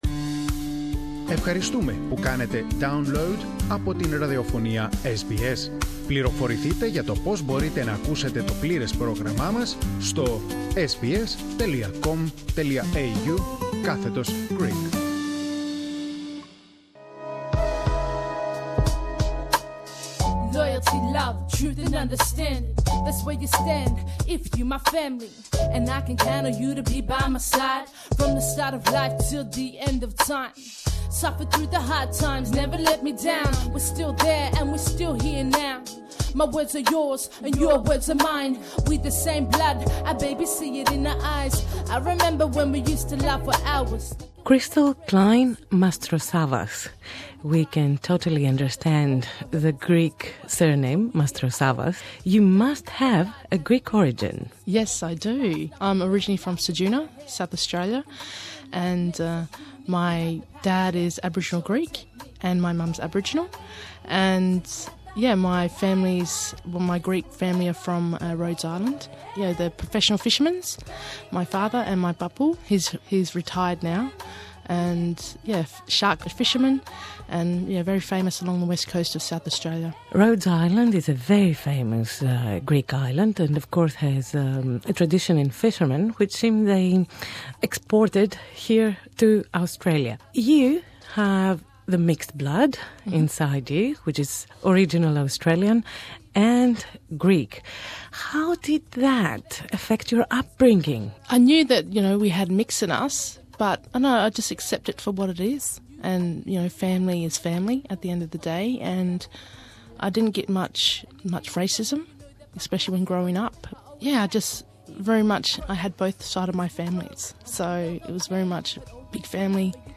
Η συνέντευξη είναι στην αγγλική γλώσσα.